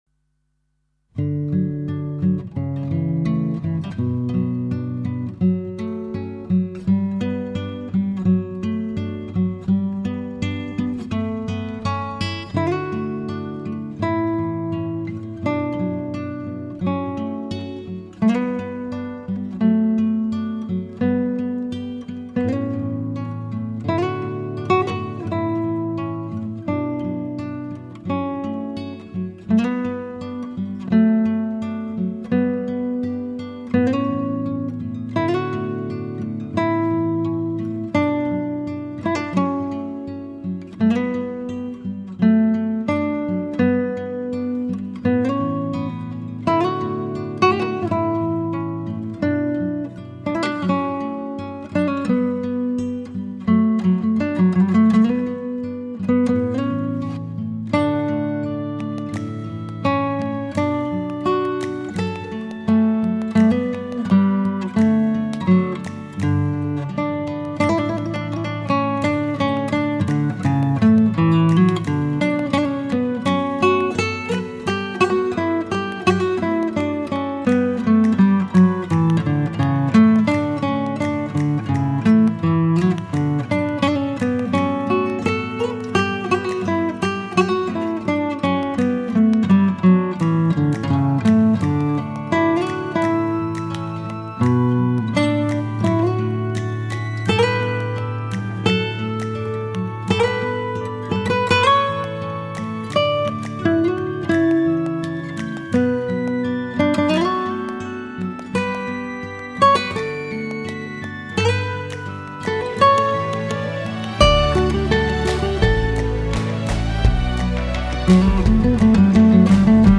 吉他演奏
低频沉实 高音灵动 中音温暖 松香味十足
简单的吉他 干净的声音 吟唱着正在消逝的时光